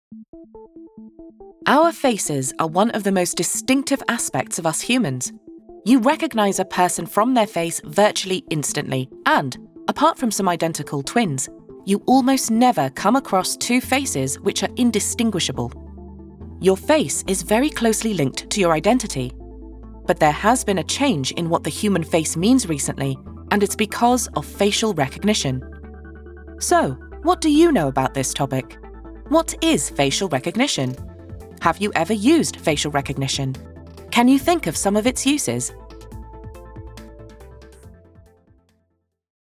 Inglês (britânico)
Amostras de Voz Nativa
E-learning
With a lifetime of acting experience under my belt, I’ve honed my craft to deliver a voice that’s luxurious, inspiring, versatile, rich, and clear.
Sennheiser MK4 condenser microphone, Presonus Audiobox iOne interface, Studio One 5, sound treated booth.
Jovem adulto
Mezzo-soprano
ConversacionalEsquentarVersátilInformativoAutoritárioReconfortante